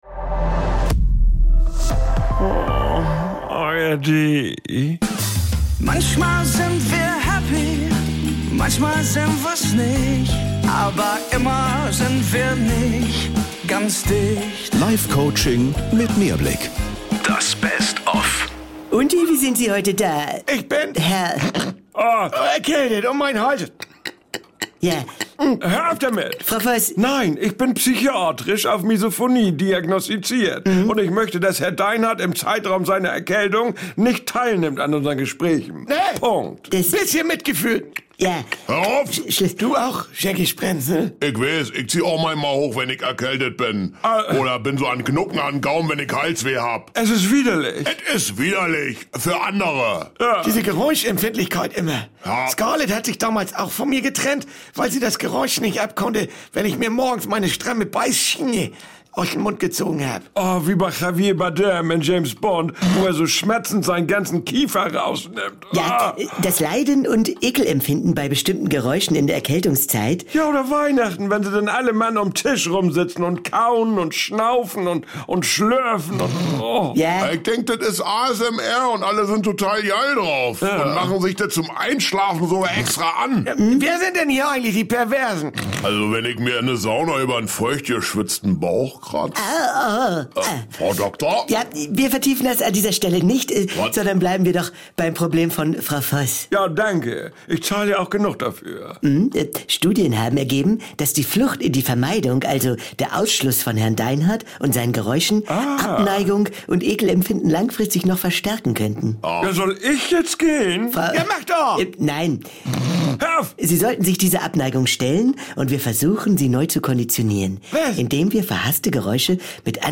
Triggerwarnung: Es folgen gleich widerliche Geräusche!